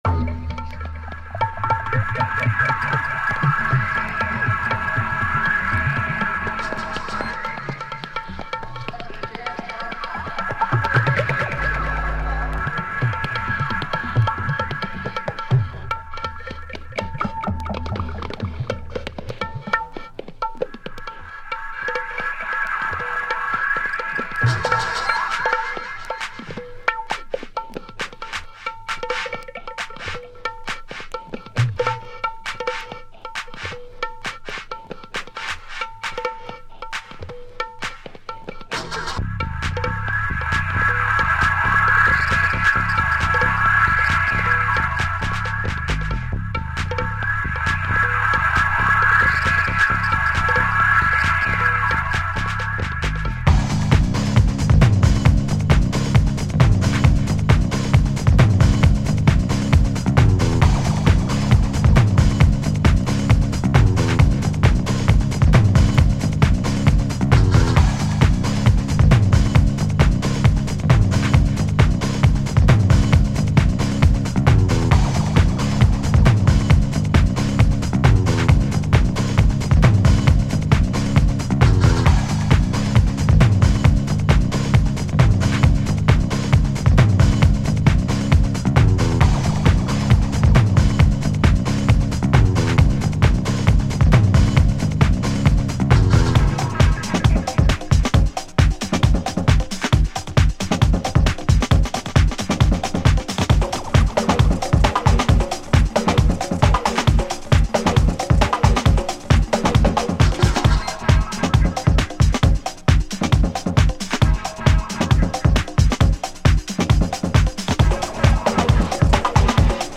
this mix from 1999 download: ▼